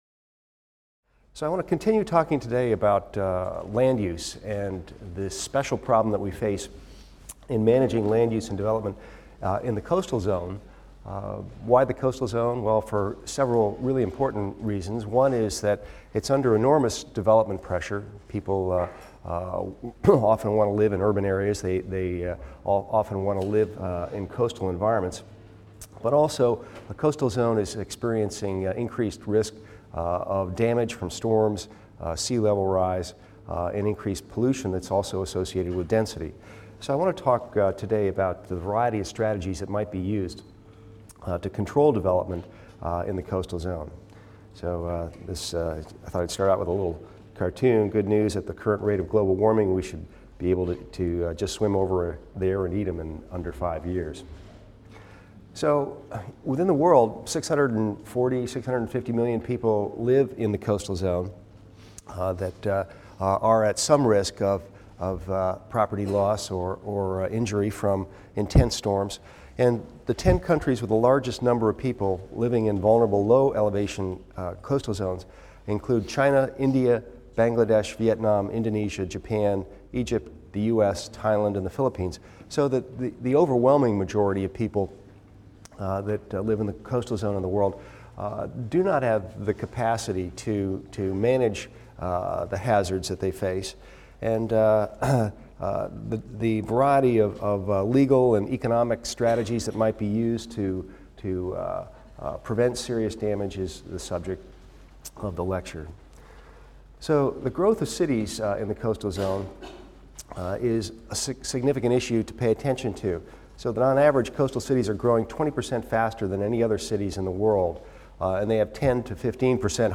EVST 255 - Lecture 20 - Managing Coastal Resources in an Era of Climate Change | Open Yale Courses